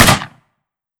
12ga Pump Shotgun - Gunshot B 005.wav